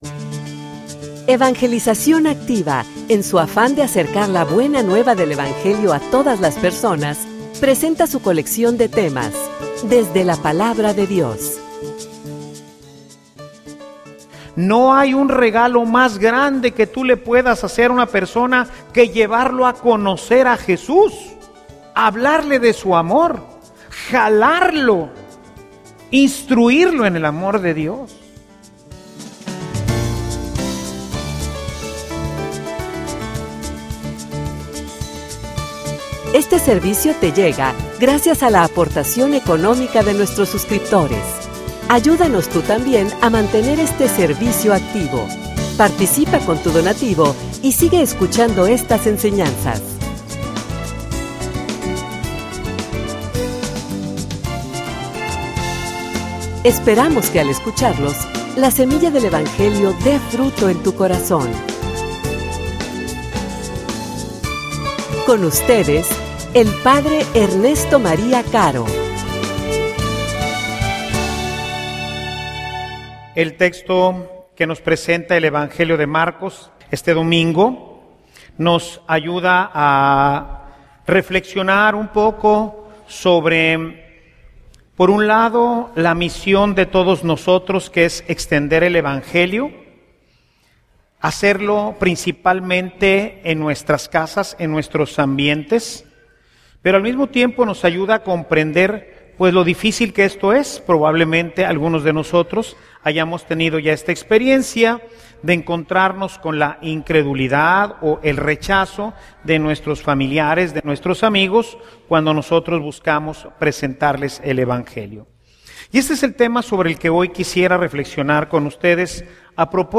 homilia_Instrumento_de_la_gracia_de_Dios.mp3